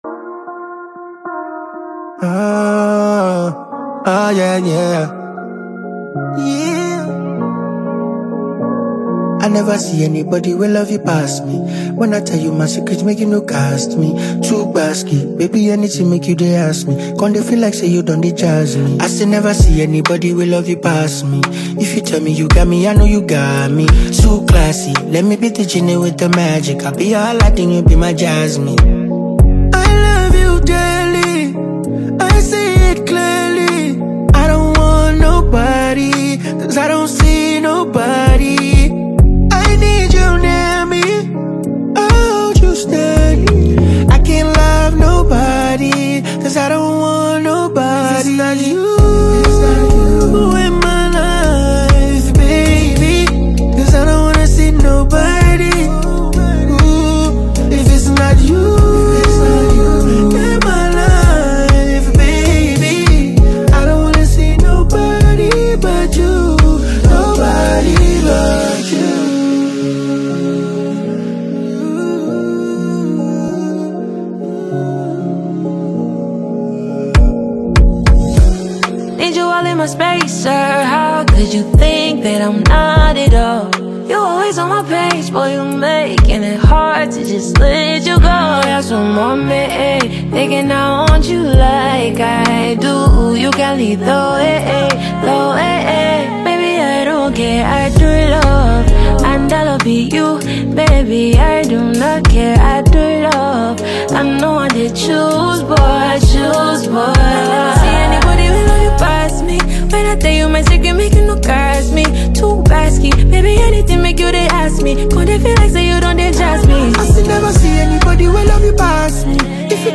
smooth, emotional record that hits straight to the heart.
Sweet, honest, and unforgettable.